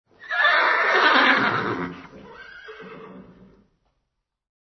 Descarga de Sonidos mp3 Gratis: relincho 7.